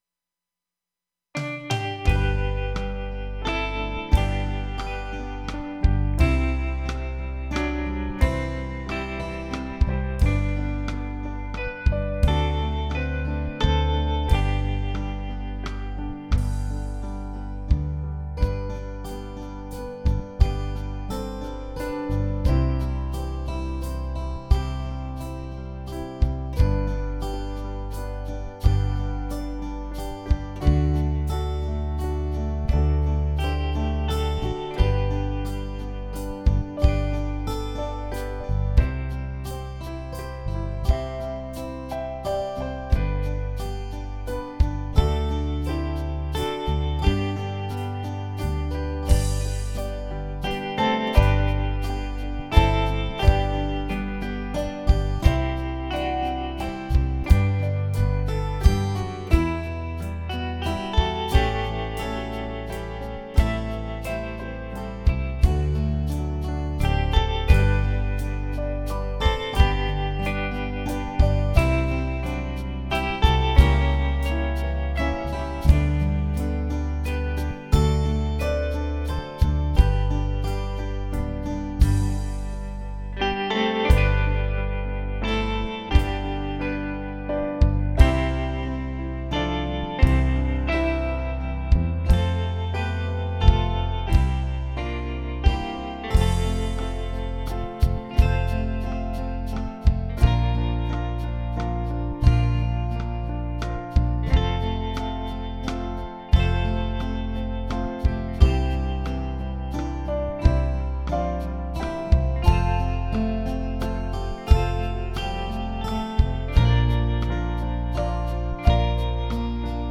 Listen to the Accompaniment Trax:
Accompaniment track provides strong support for the voices.